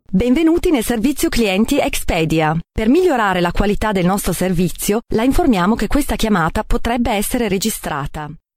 Female; 20s/30s, teenager - sunny versatile voice over - modern, fresh and lively, sexy and mellow, native Italian speaker. Standard Italian accent.
Sprecherin italienisch, Muttersprachlerin.
Sprechprobe: Industrie (Muttersprache):